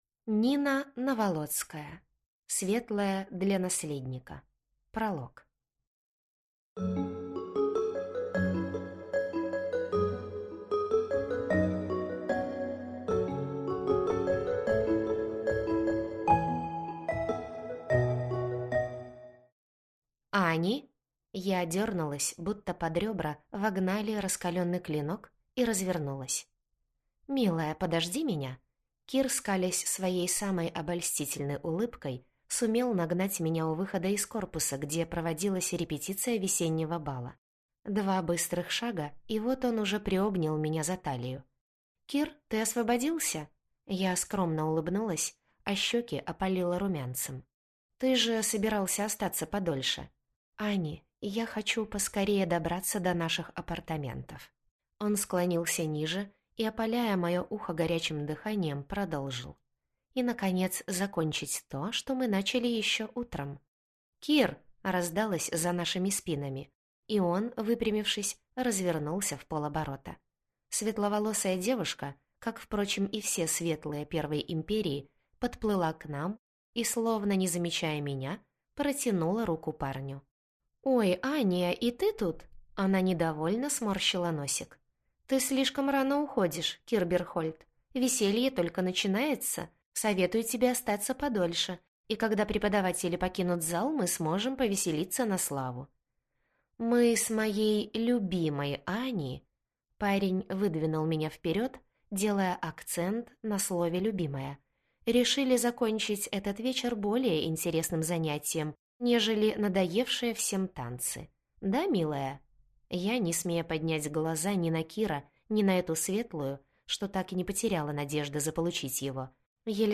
Аудиокнига Светлая для наследника | Библиотека аудиокниг